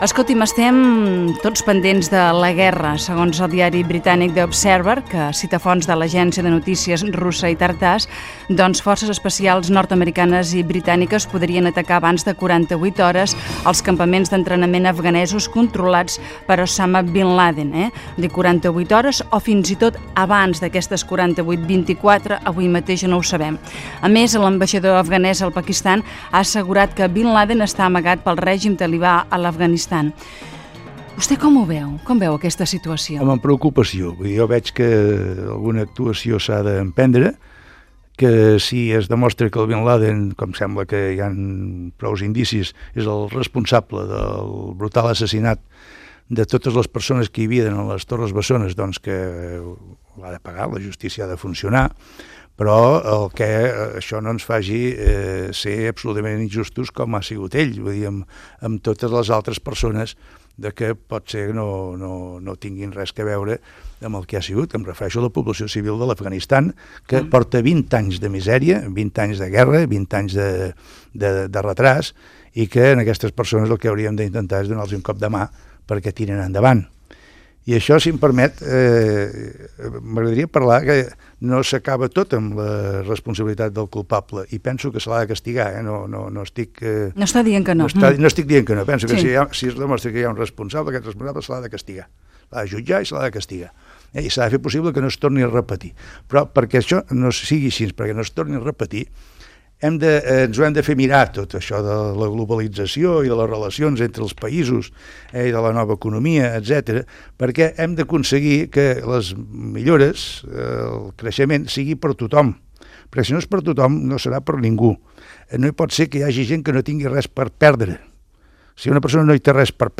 Catalunya en plural Gènere radiofònic Entreteniment
Fragment extret de l'arxiu sonor de COM Ràdio.